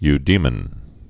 (y-dēmən)